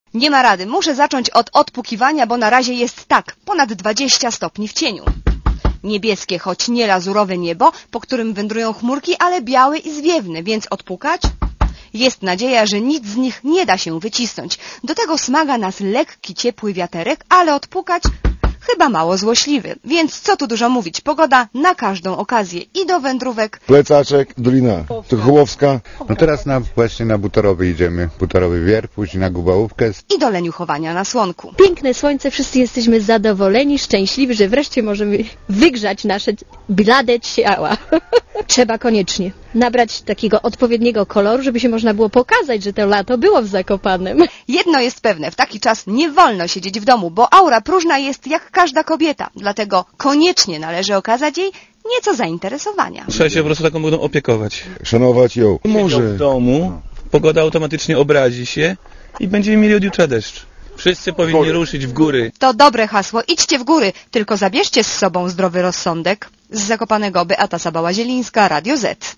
Posłuchaj relacji z Zakopanego